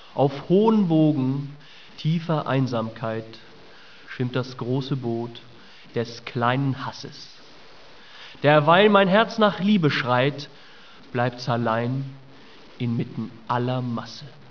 typische poetische Varieté-Stimmung